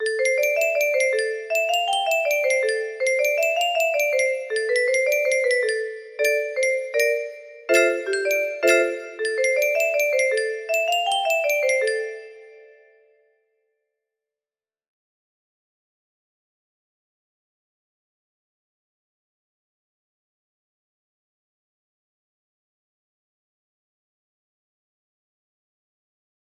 ME music box melody